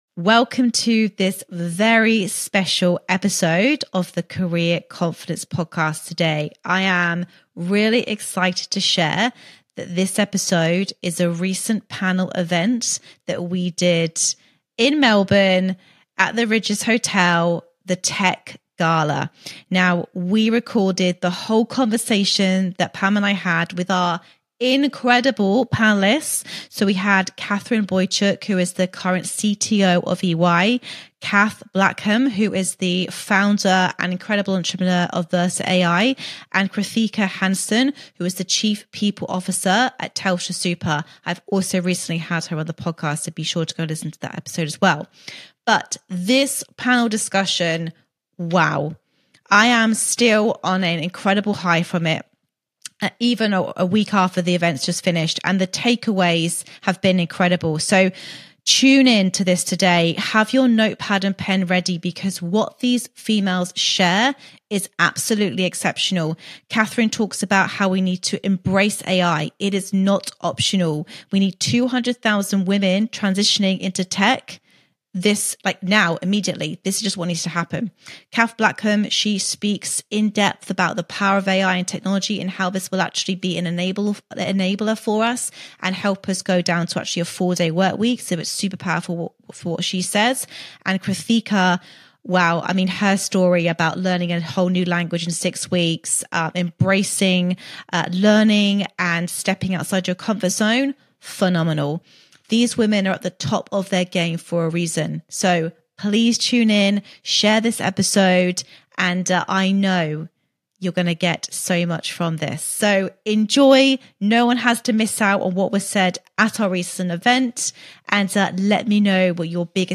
DELETE GOOGLE NOW! And Why Opting Out of Tech is NOT A LUXURY - Shaping the Future of AI and Leadership [Tech Gala 2024 LIVE]